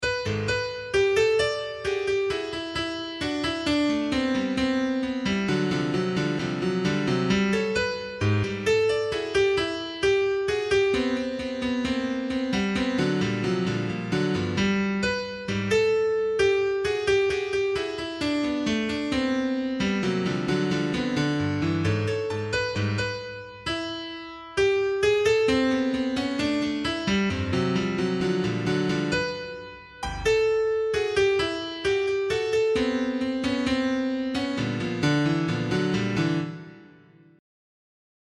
The generator then modifies the music based on the critic's feedback until it closely resembles real music from the training dataset. Listen to an example of the generated music:
MuseGAN_song.mp3